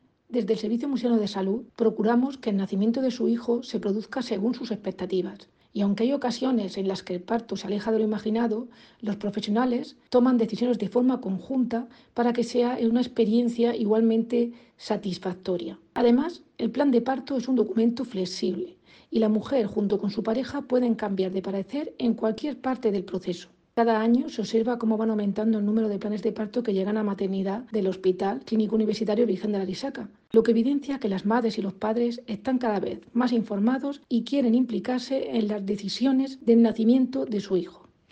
Declaraciones de la directora general de Atención Hospitalaria, Irene Marín, sobre el 'Plan de parto y nacimiento'.